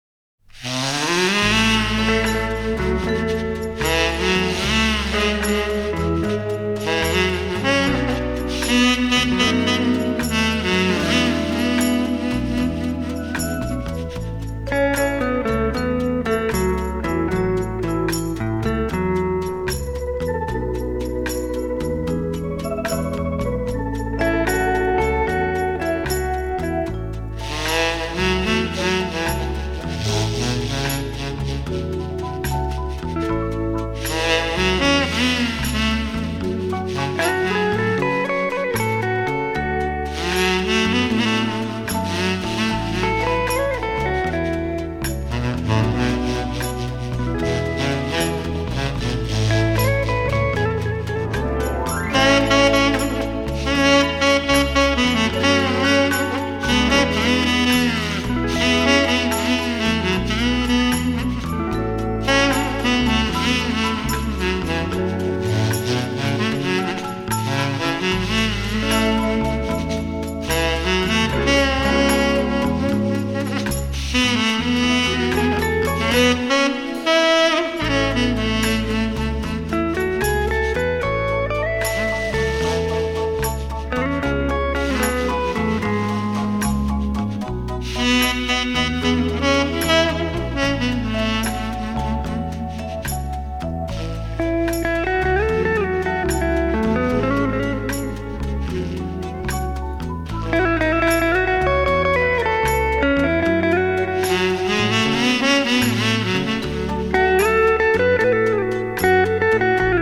★ 演歌界無可取代的重量級吉他大師，靈巧指法與動人尾韻無間斷挑動您的耳朵！
★ 日本壓片，錄音清澈透明，吉他形體極富肉感，帶來最發燒最立體的演歌饗宴！